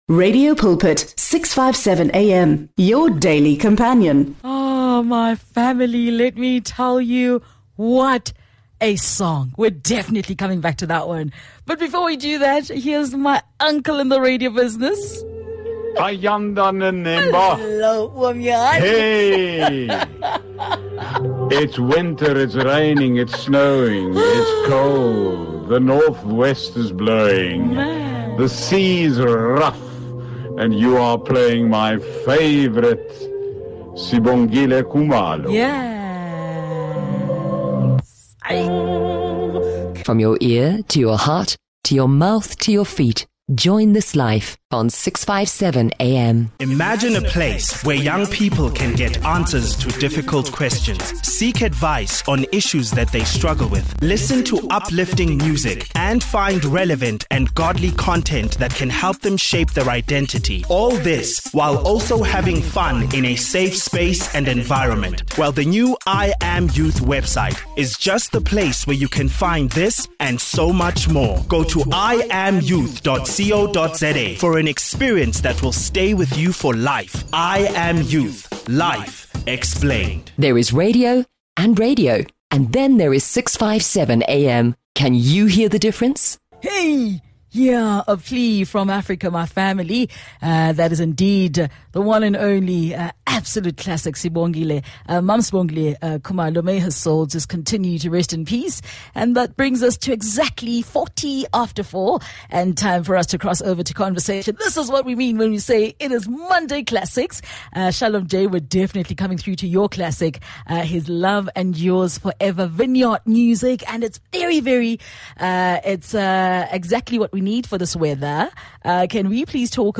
ENGLISH SOUTH AFRICA